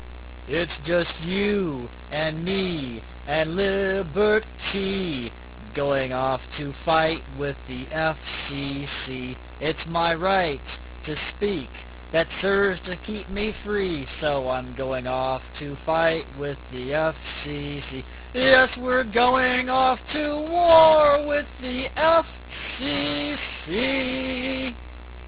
Click this to hear me sing (40K).